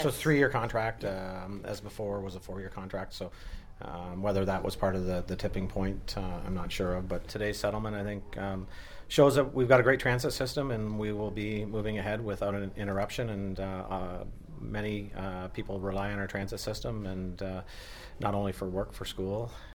Last night Belleville Mayor Neil Ellis said he’s happy with the new agreement:
mayor-neil-ellis-bus-deal.mp3